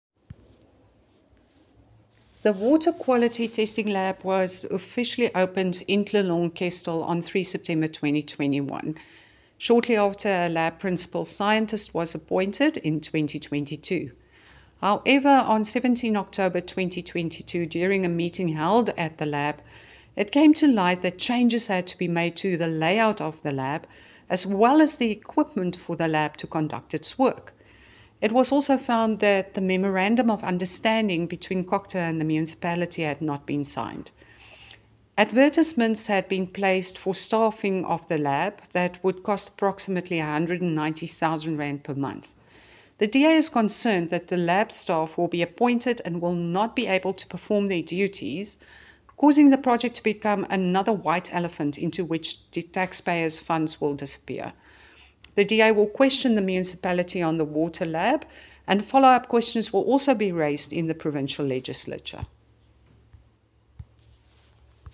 Afrikaans soundbites by Cllr Bea Campbell-Cloete